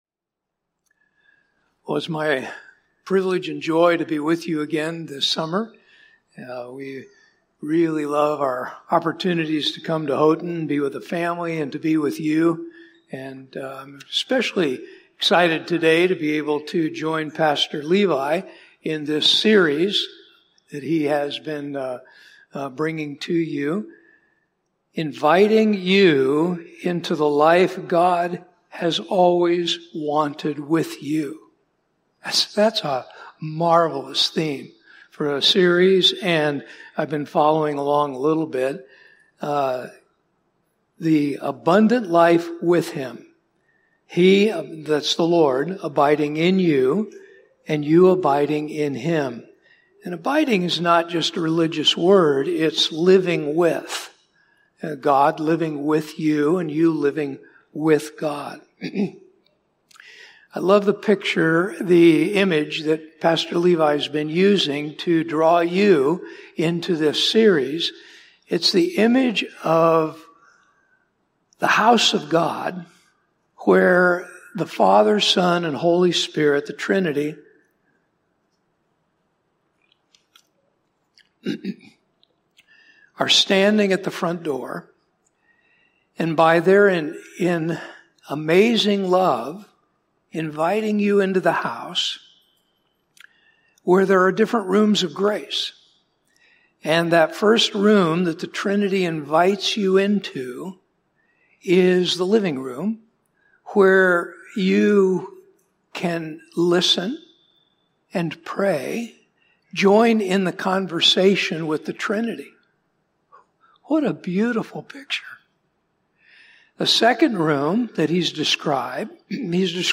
This podcast episode is a Sunday message from Evangel Community Church, Houghton, Michigan, July 27, 2025.